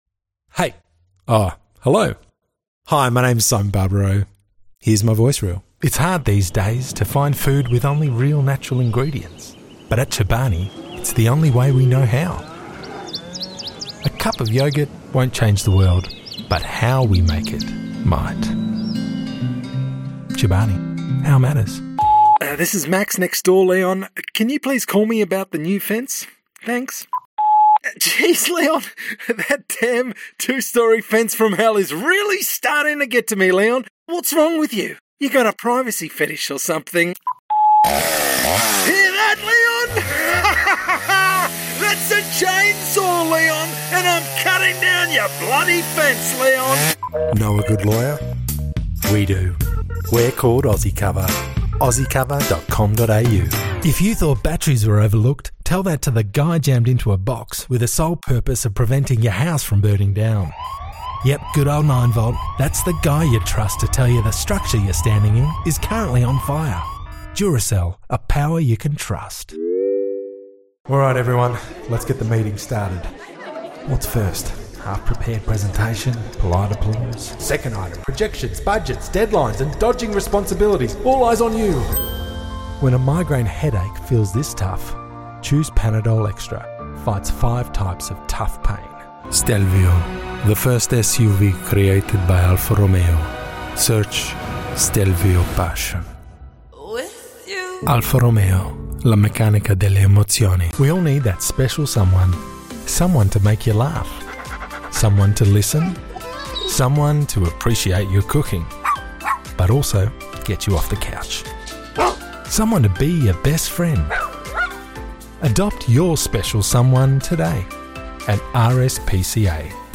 Voiceover Reel - Promo
Middle Aged
Clients praise my firm-but-gentle delivery, trustworthy tone, and authentic, motivating style; ideal for brands that want to connect with real people, not just talk at them.
I operate from a professional-grade home studio, equipped with a RØDE NT1 microphone and Logic Pro, delivering high-quality audio tailored to your project’s specs — fast, clean, and professional.